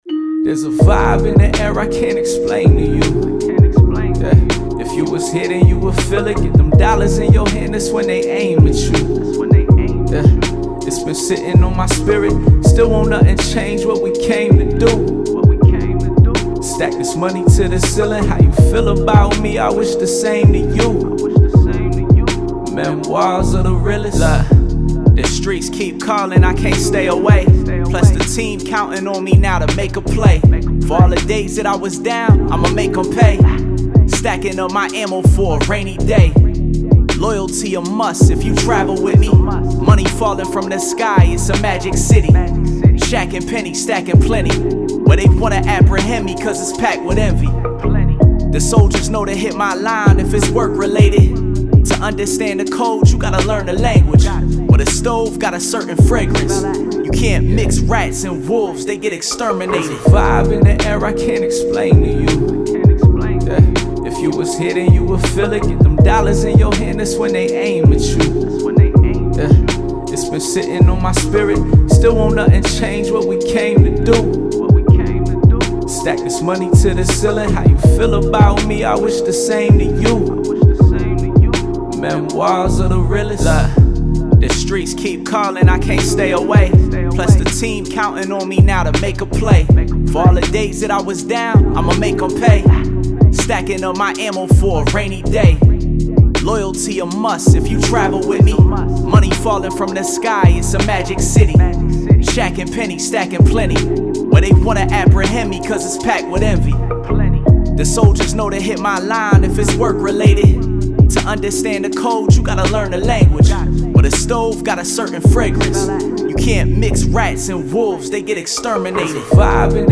Hip Hop
Bb Minor